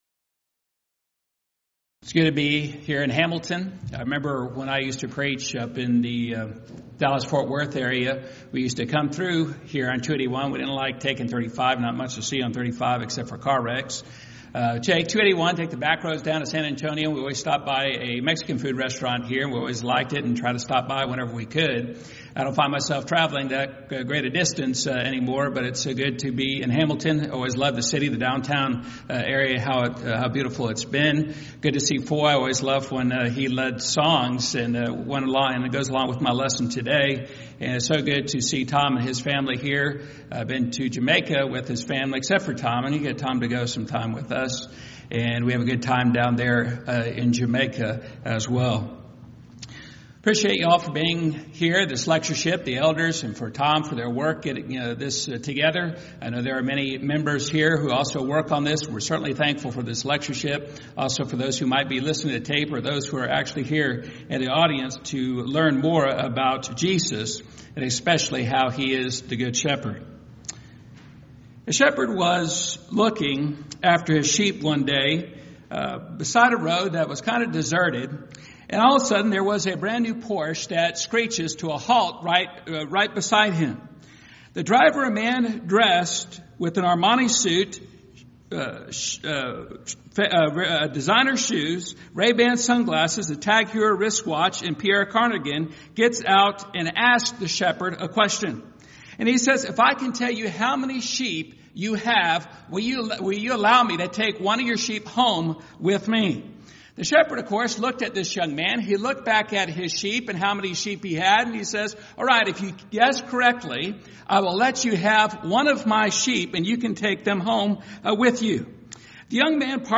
Alternate File Link File Details: Series: Back to the Bible Lectures Event: 8th Annual Back To The Bible Lectures Theme/Title: Do You Know The Christ?